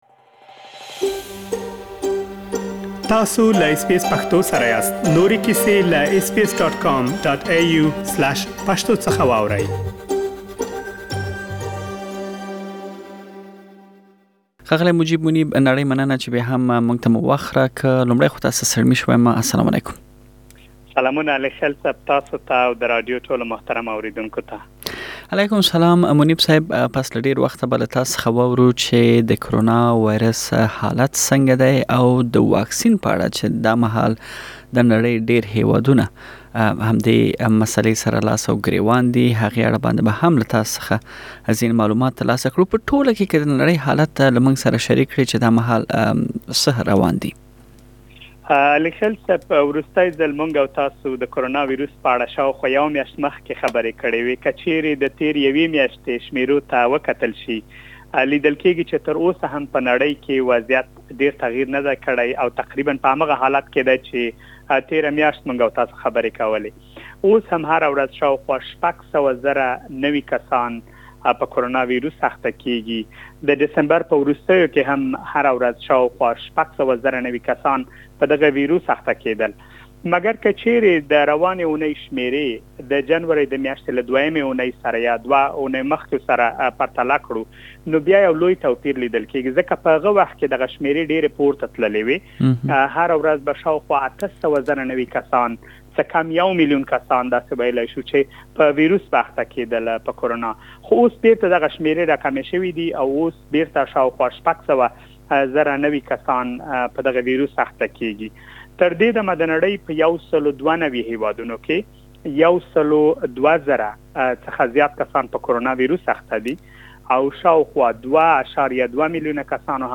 آسټرالیا غواړي ترڅو د فبرورۍ د میاشتې په ورستیو کې د کرونا وېروس د واکسین تطبیق پیل کړي د کرونا وېروس د واکسینونو په اړه لا ډېر معلومات په مرکې کې اوریدلی شئ.